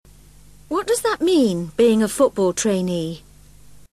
PITCH IN BRITISH ENGLISH
After listening to each audio file, repeat it aloud trying to imitate the intonation:
ACQUAINTANCE TO ACQUAINTANCE